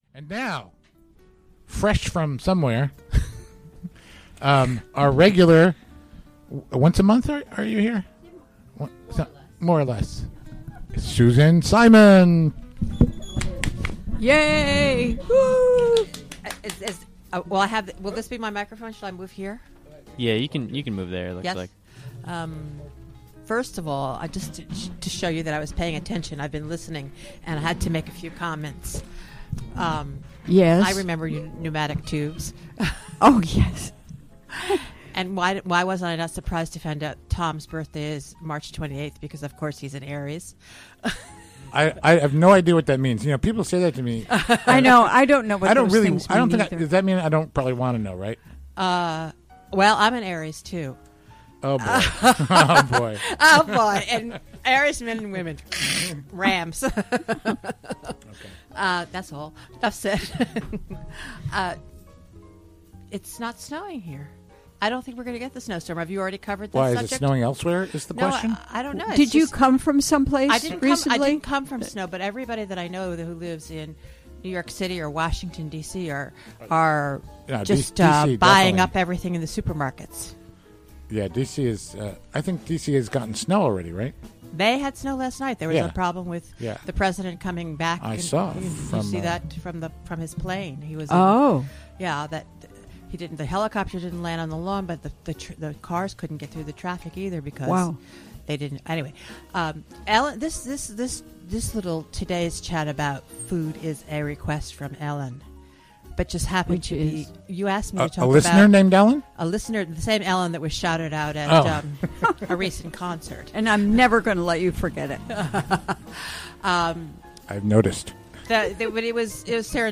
Recorded during the WGXC Afternoon Show, Thu., Jan. 21, 2016.